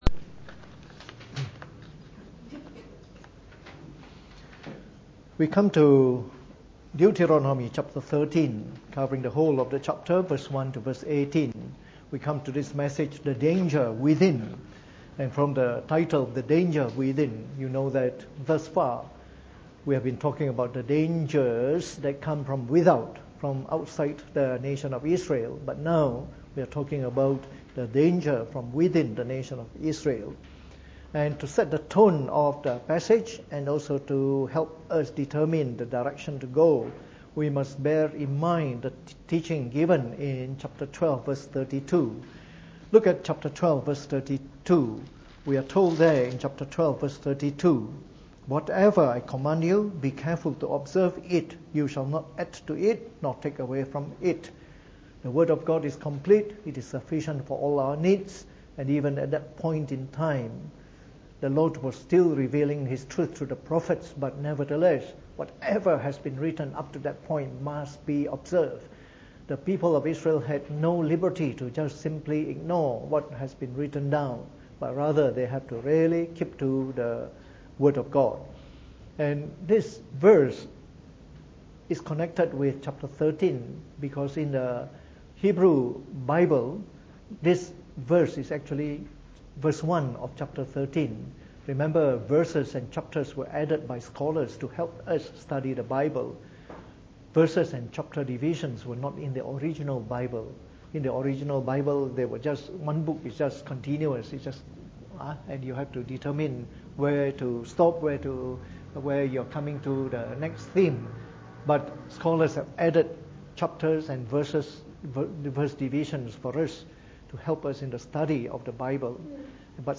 Preached on the 25th of April 2018 during the Bible Study, from our series on the book of Deuteronomy.